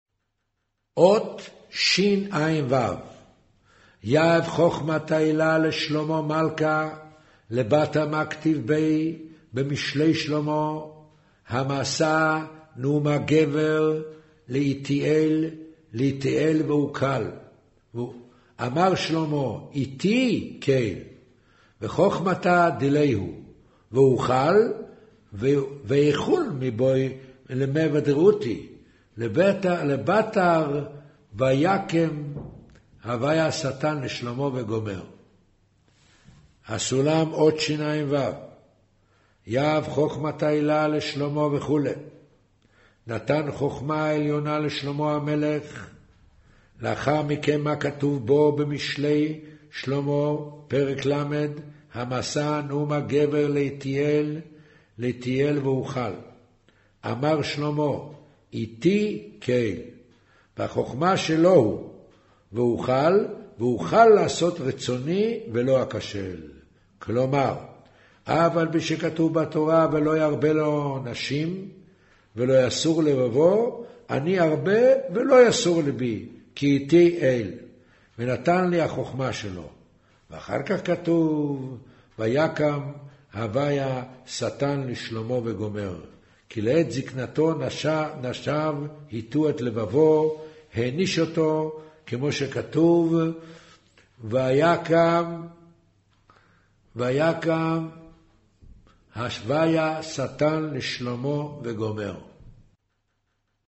אודיו - קריינות זהר, פרשת נח, מאמר ויאמר ה' הן עם אחד